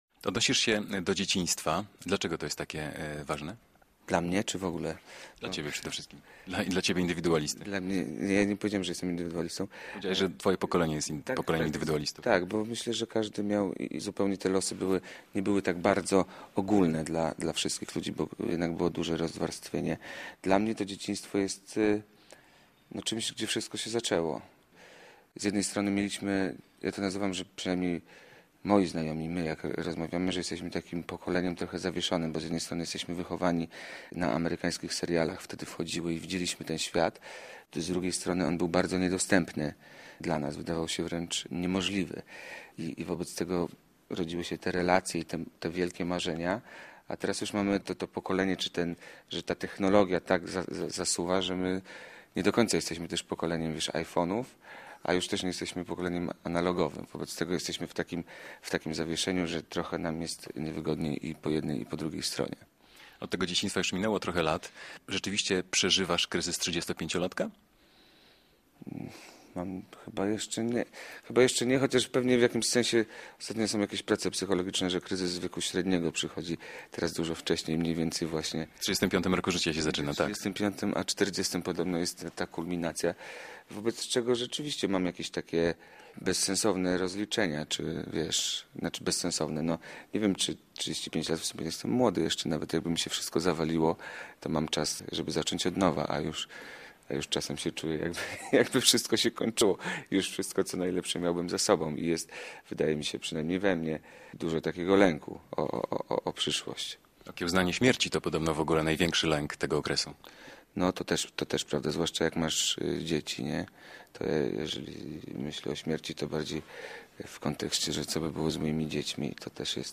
Radio Białystok | Gość | Paweł Domagała - aktor, piosenkarz